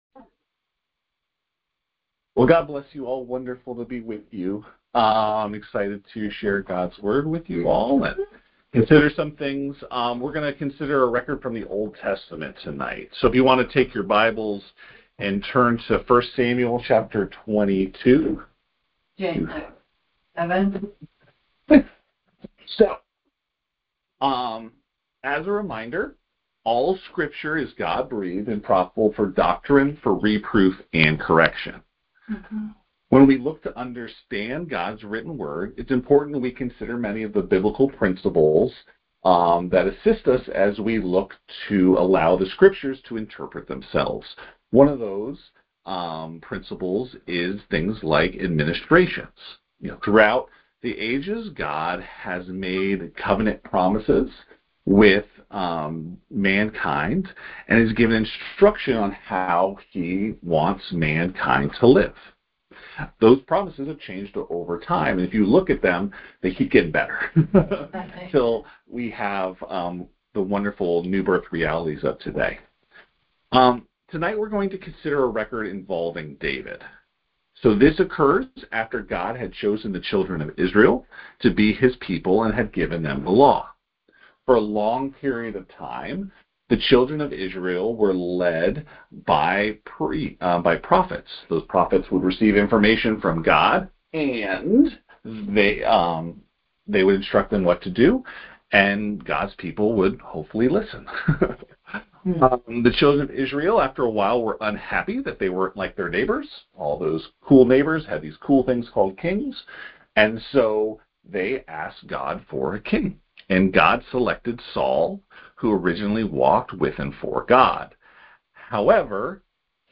Details Series: Conference Call Fellowship Date: Wednesday, 30 July 2025 Hits: 276 Scripture: 2 Timothy 1:7 Play the sermon Download Audio ( 13.45 MB )